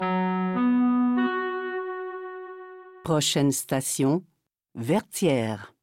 Listen to the métro voice pronounce the name Vertières